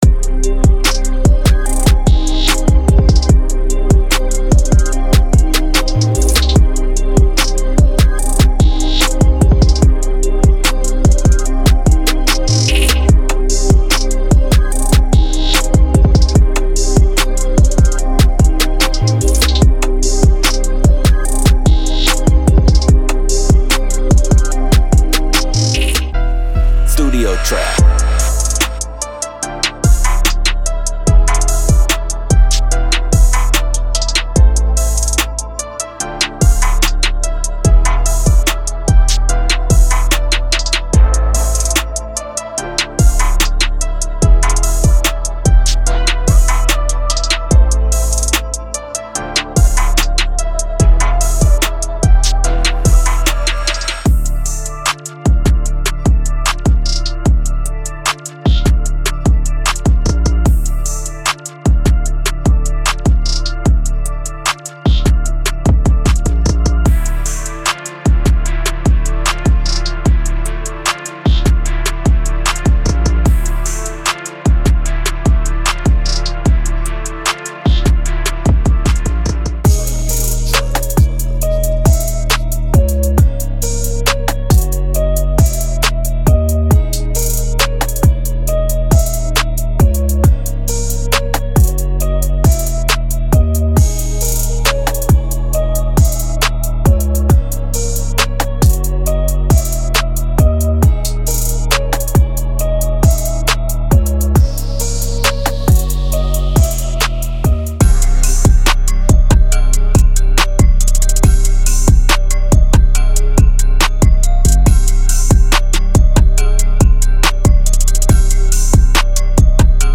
elevate their trap and hip-hop productions.
Demo